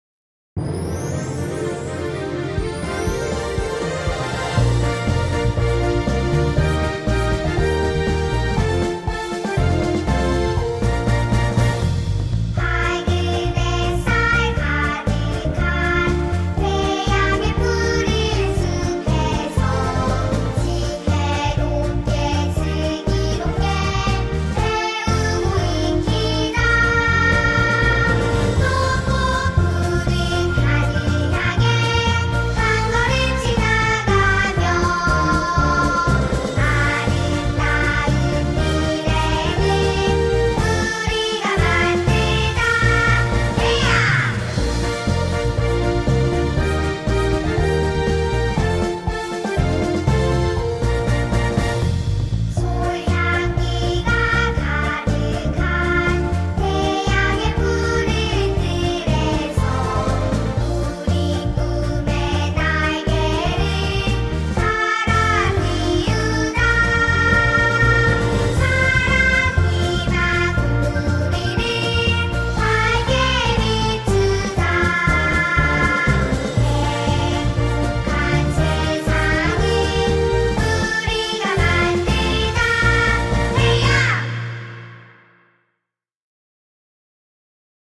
대양초등학교 교가